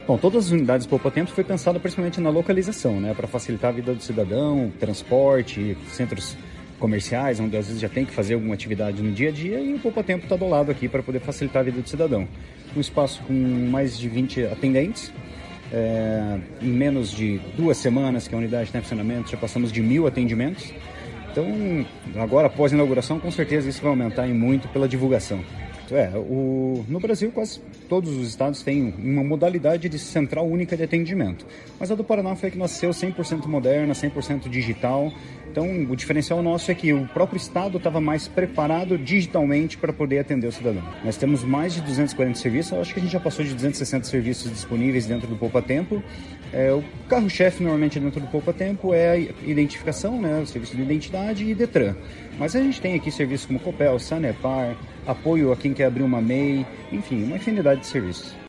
Sonora do o superintendente geral de Governança de Serviços e Dados, Leandro Moura, sobre a inauguração da 16ª unidade do Poupatempo Paraná em Arapongas